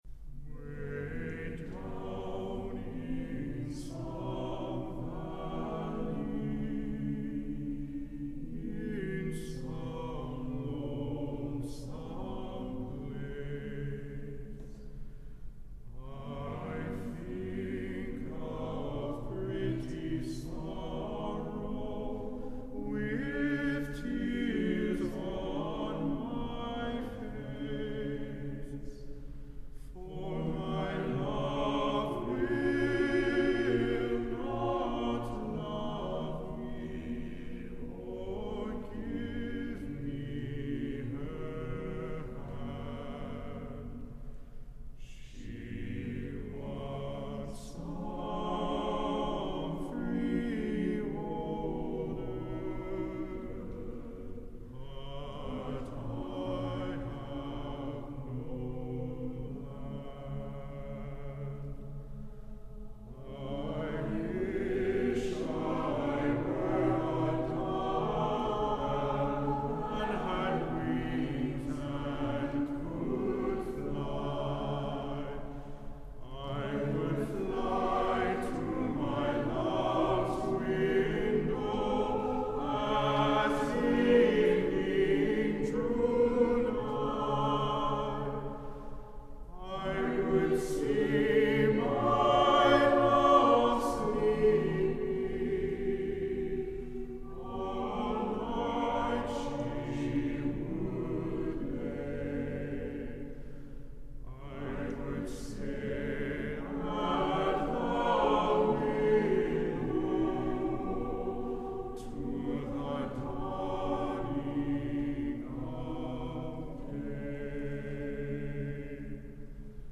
Accompaniment:      A Cappella, Baritone Solo
Music Category:      Choral